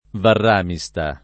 varr#miSta] top. (Tosc.) — luogo del Valdarno di Sotto, ricordato nell’800 per dòtte conversazioni: a trovare in villa il Capponi a Varràmista [a ttrov#re in v&lla il kapp1ni a vvarr#miSta] (Baldini)